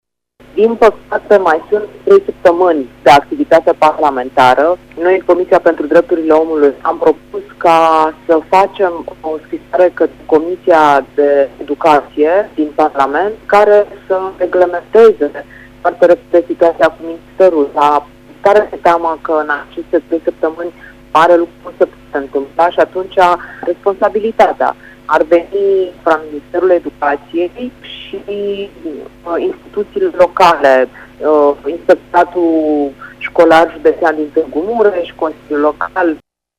Au fost făcute greșeli administrative în ceea ce privește modalitatea de înființare a liceului a precizat Adriana Săftoiu: